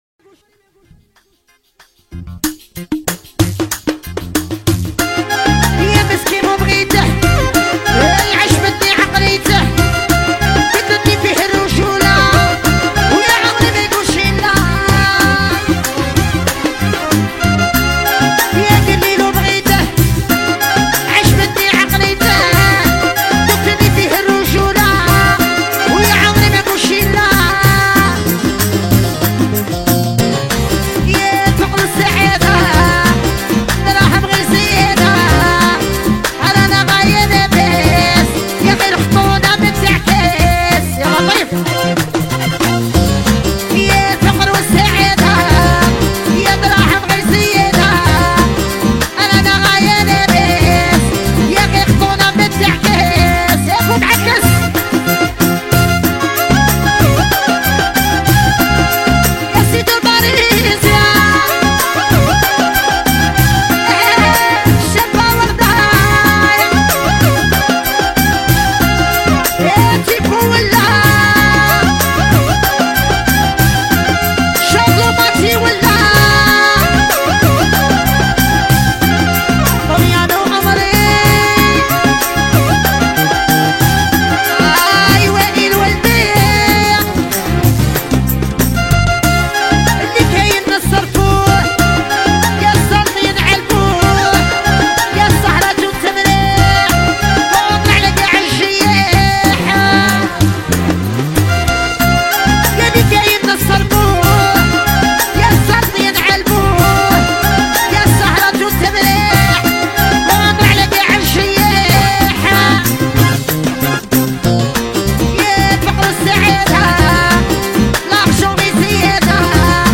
اغاني الراي استماع song النسخة الأصلية 2020 MP3